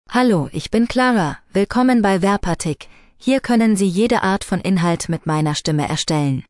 FemaleGerman (Germany)
ClaraFemale German AI voice
Voice sample
Listen to Clara's female German voice.
Female
Clara delivers clear pronunciation with authentic Germany German intonation, making your content sound professionally produced.